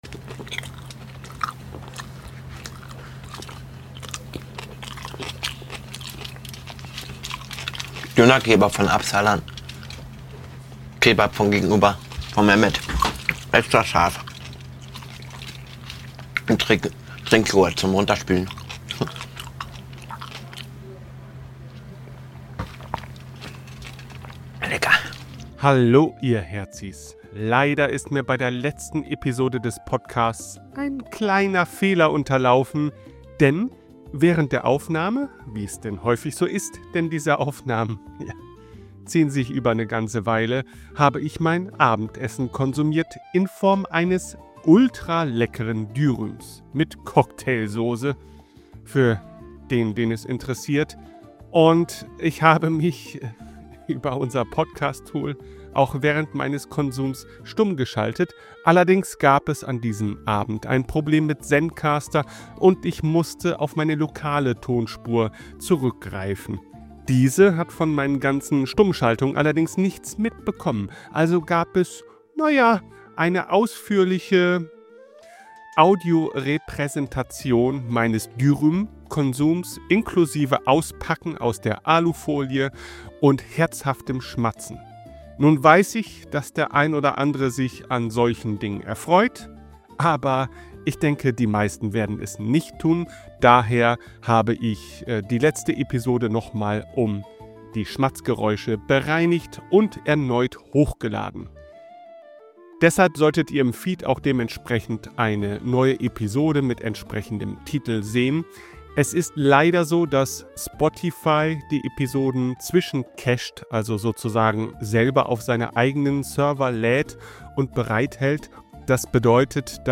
Es wurde keine generative KI verwendet.
Ambient Sounds